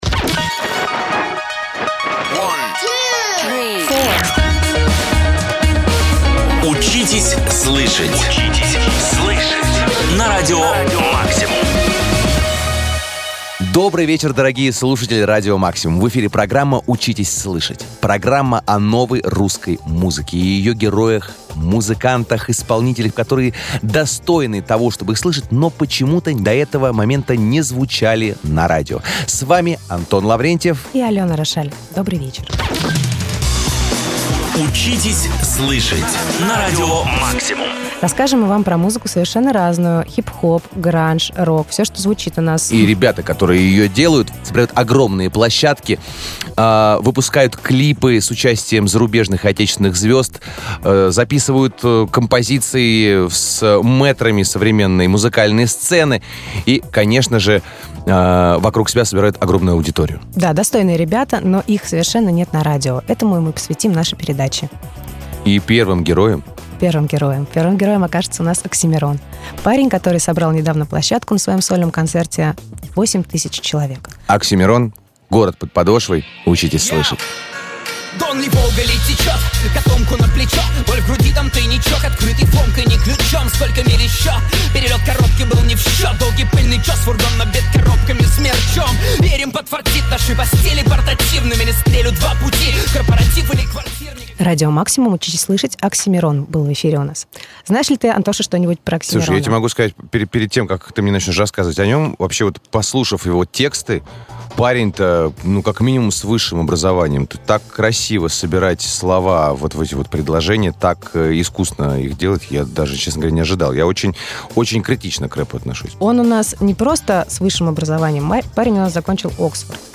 Теперь раз в неделю целый час слушателей будут знакомить с молодыми музыкантами, песни которых стоят на повторе в плей-листах пользователей соцсетей и набирают просмотры на Youtube. Запись первого эфира программы «Учитесь слышать» 12 мая 2016 года.